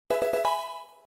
level up.mp3